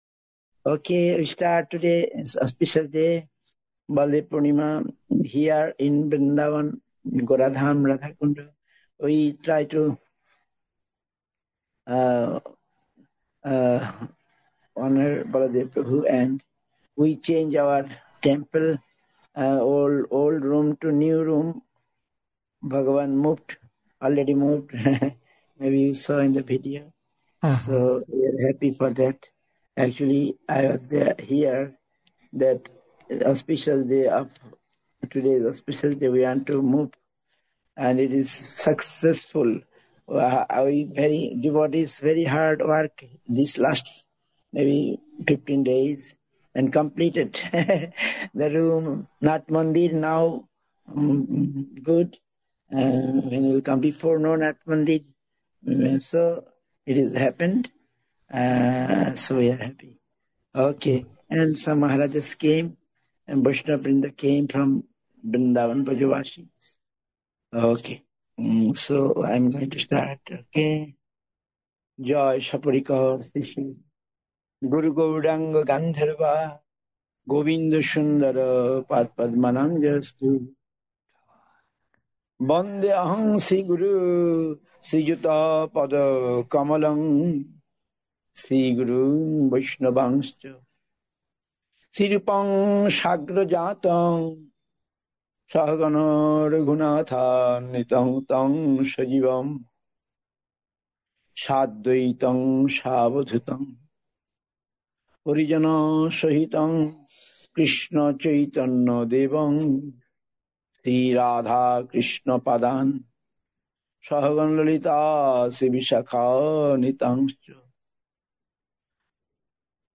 India, Nabadwip Dham, SREE CAITANYA SRIDHAR SEVA ASHRAM.